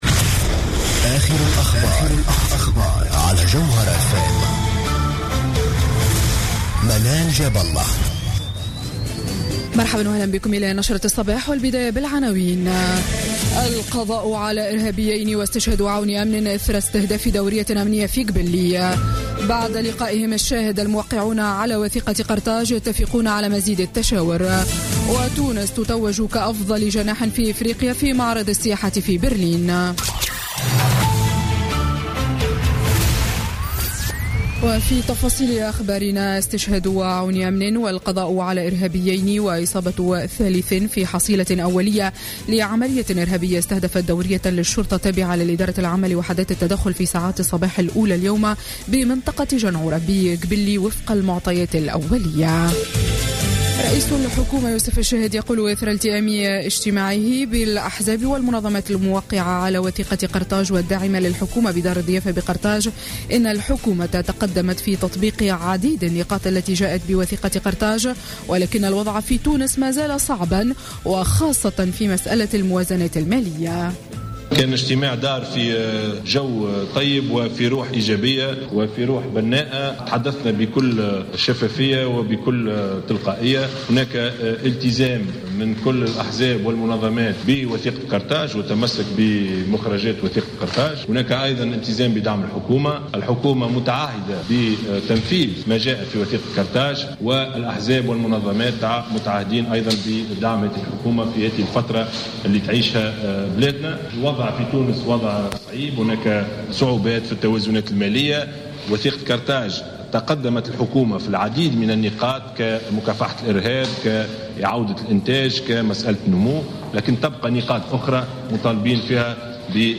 نشرة أخبار السابعة صباحا ليوم الأحد 12 مارس 2017